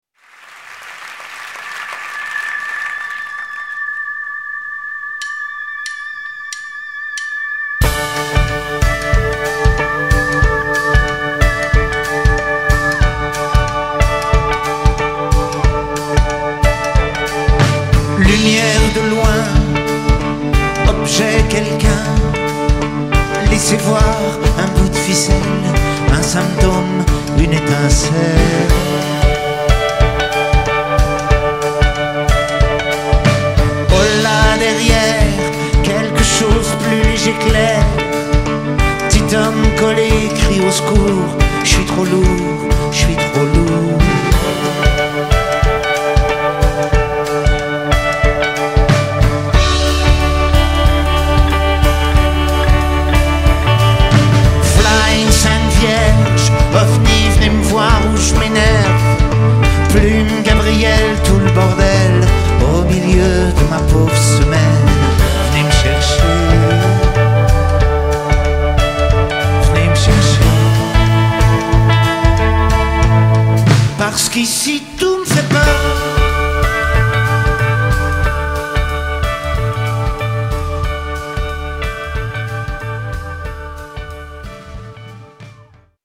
tonalité Ré majeur